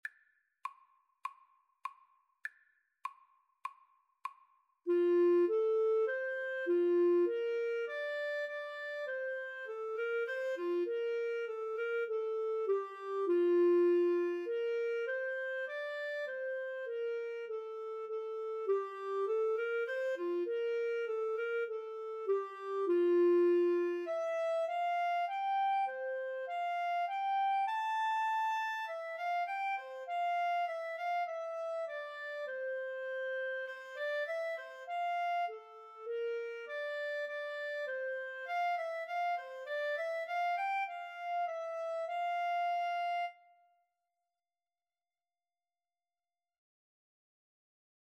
Free Sheet music for Clarinet Duet
4/4 (View more 4/4 Music)
F major (Sounding Pitch) G major (Clarinet in Bb) (View more F major Music for Clarinet Duet )